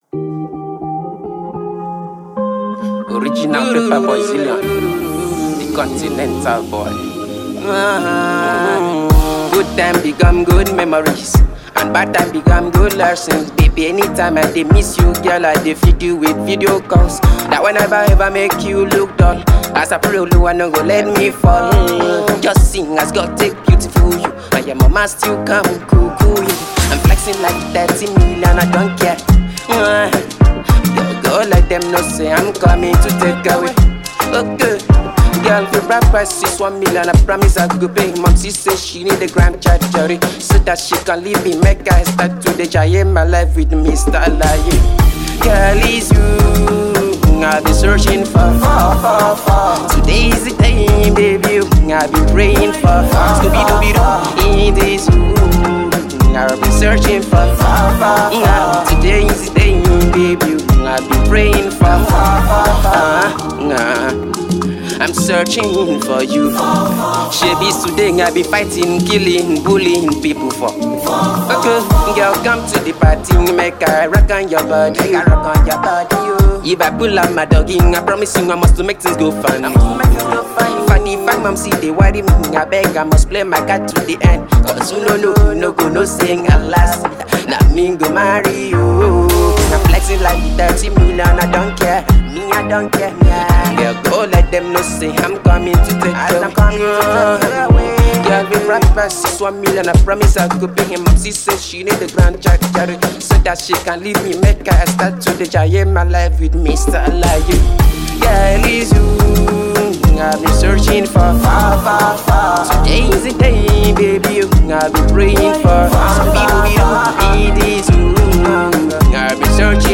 Fast rising Afro singer